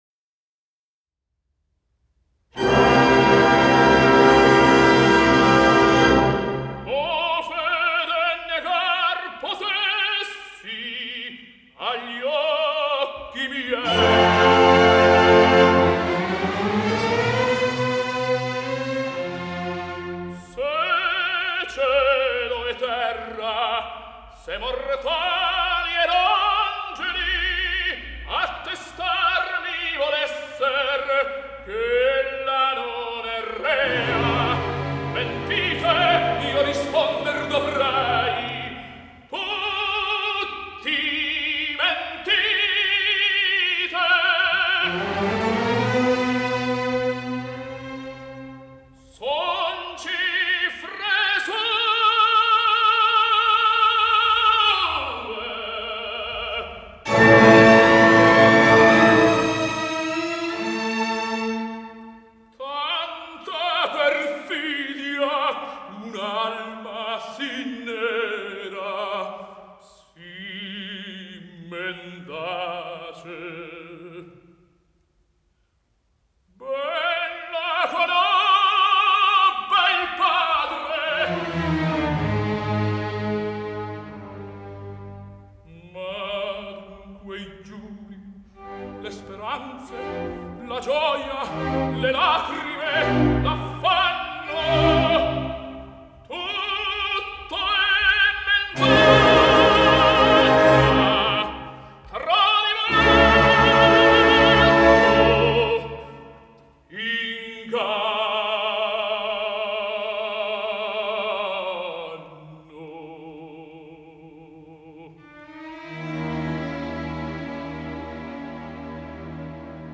Classical, Opera, Vocal